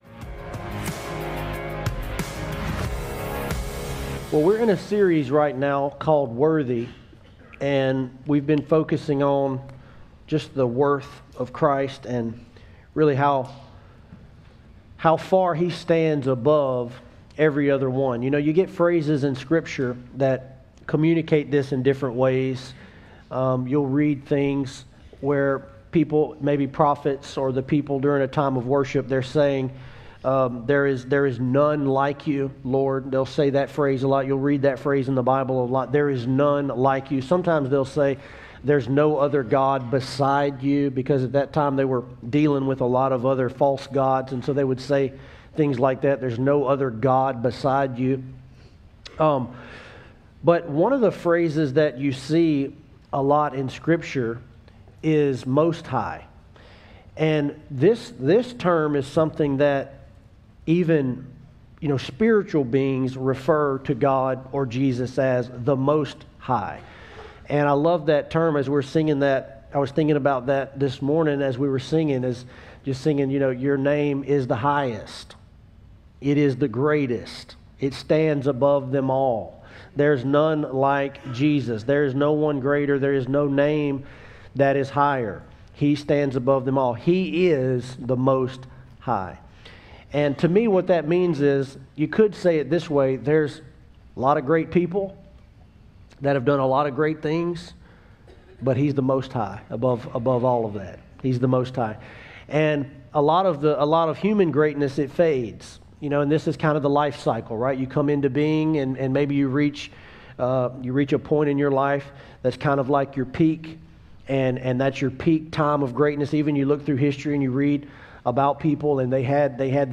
Sermons from One Life Church Alexandria